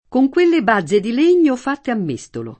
koj kU%lle b#zze di l%n’n’o f#tte a mm%Stolo] (Malaparte)